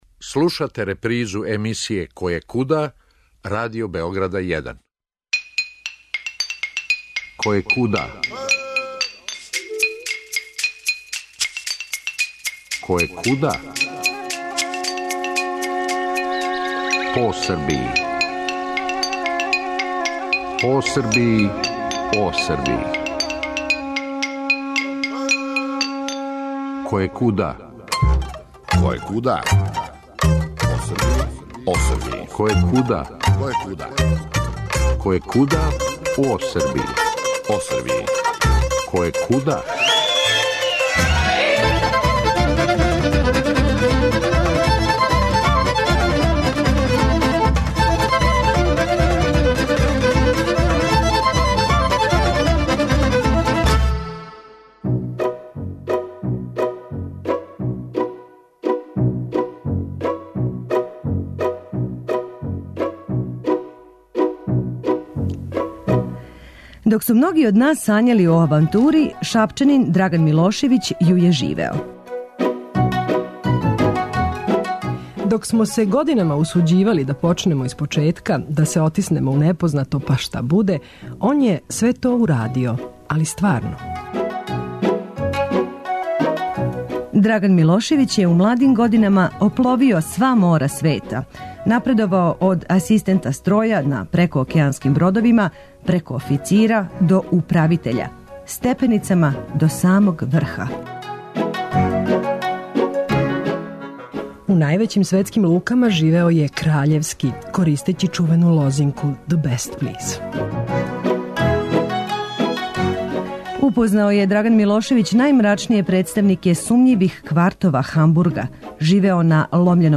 Можемо рећи да вам нудимо радијски филм у два дела, макар колико то чудно звучало.